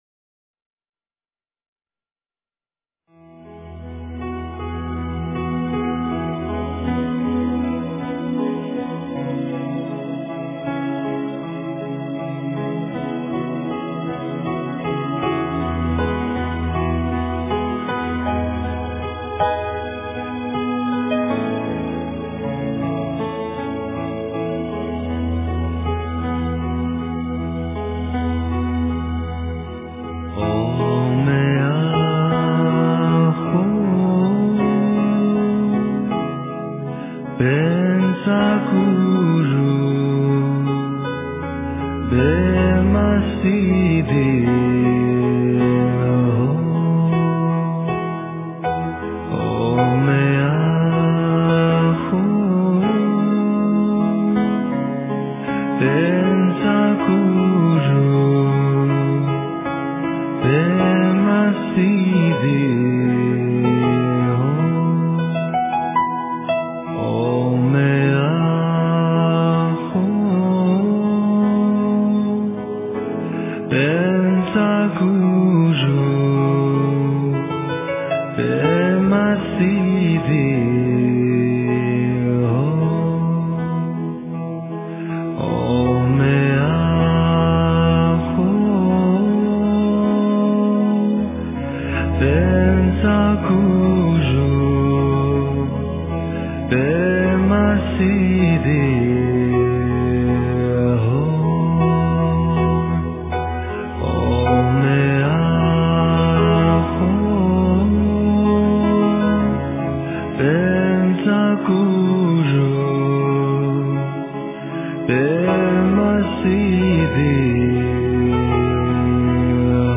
诵经
佛音 诵经 佛教音乐 返回列表 上一篇： 心经-梵音 下一篇： 大悲咒 相关文章 法华经-随喜功德品第十八 法华经-随喜功德品第十八--未知...